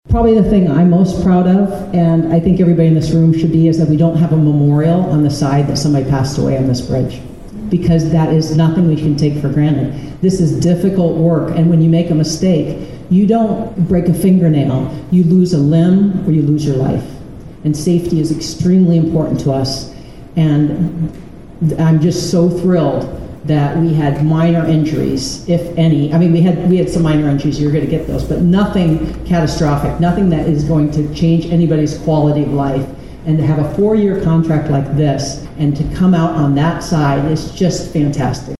Various local, state and federal dignitaries gathered on Tuesday (March 25, 2025) to hold the official ribbon cutting ceremony for the new Lieutenant Commander John C. Waldron Memorial Bridge over the Missouri River between Fort Pierre and Pierre.